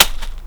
my field recorder is just an old MD walkman with a stereo condenser mic i picked up on impulse in NYC some years back. it’s not as handy as the new models from the likes of zoom, but i was sure it would suffice for this job.
bags on gravel gave an excellent source for a crunchy snare drum, and dropping a somewhat denser package on the deck lent itself to a kick drum. the recording also picked up some breathy childish sniggering which i thought could pass as a 909 hat.
sb1_gravel.wav